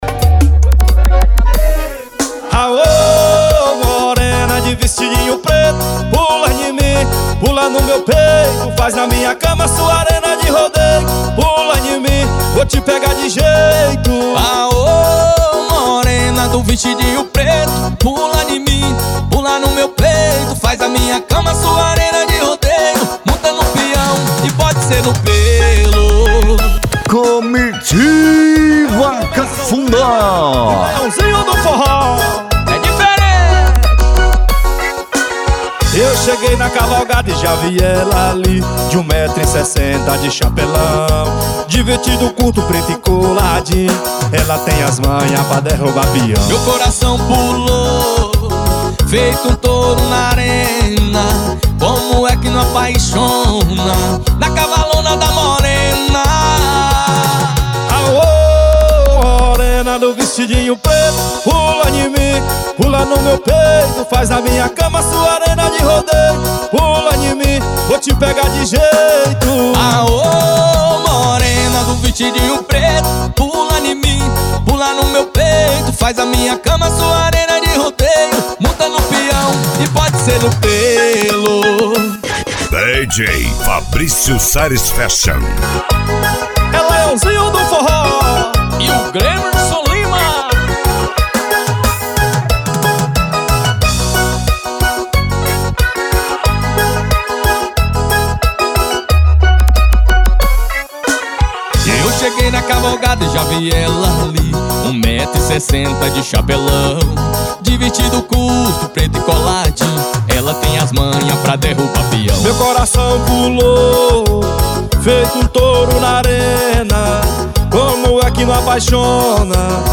Arrocha
Funk
SERTANEJO
Sertanejo Universitario
Sets Mixados